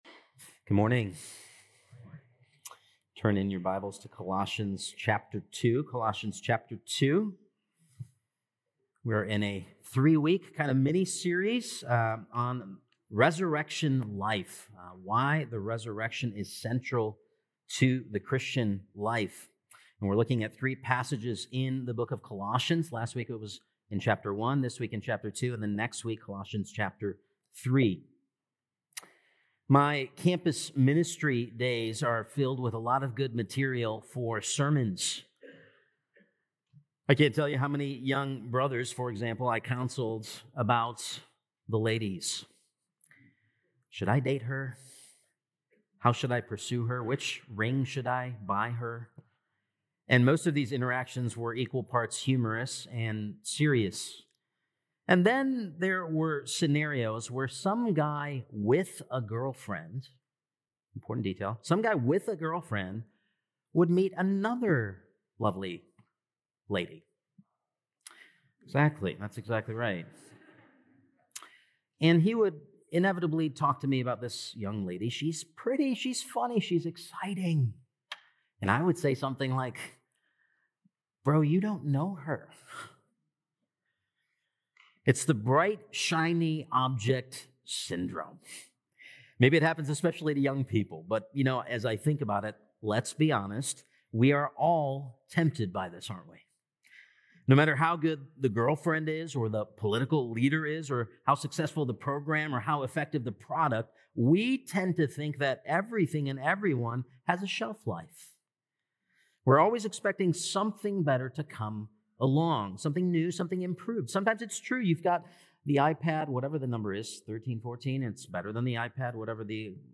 Apr 27th Sermon | Colossians 2:6-15